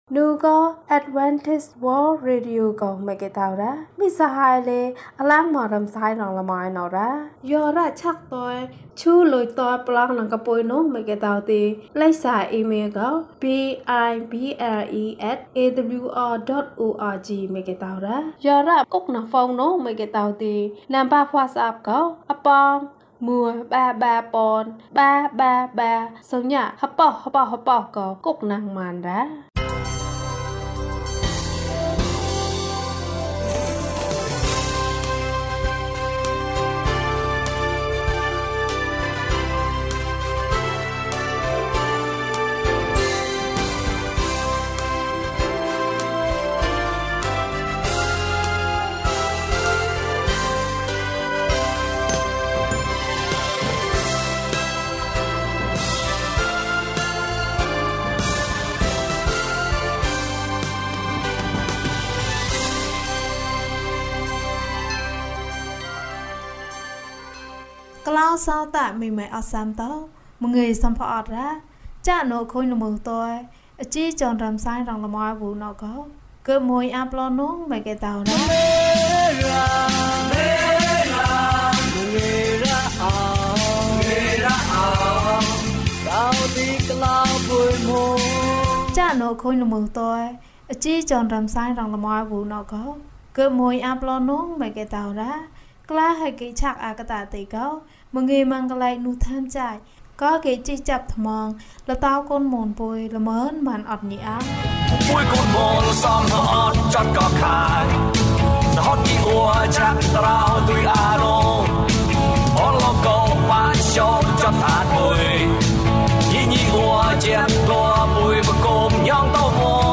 ဘုရားသခင်ထံမှကောင်းကြီးမင်္ဂလာ။၂၆ ကျန်းမာခြင်းအကြောင်းအရာ။ ဓမ္မသီချင်း။ တရားဒေသနာ။